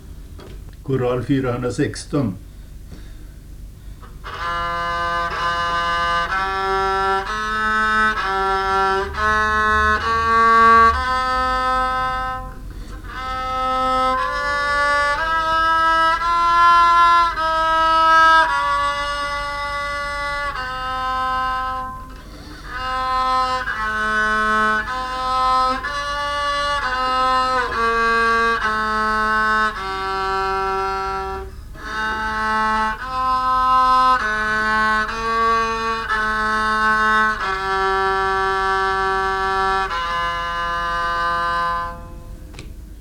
psalm
pris och ära vare dig på psalmodikon